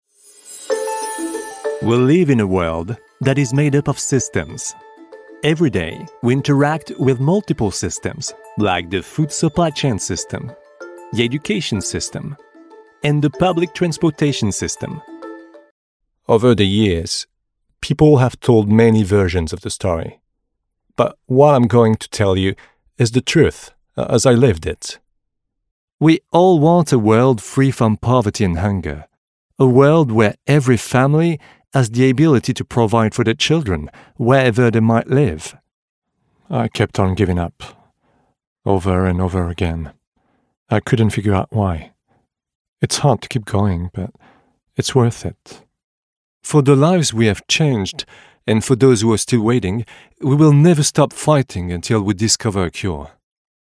Male
My voice is warm, friendly and approachable, making it great for any story telling, with a natural and personal touch.
English French Accent Demo
Words that describe my voice are friendly, warm, natural.
All our voice actors have professional broadcast quality recording studios.
1106english_french_accent_demo.mp3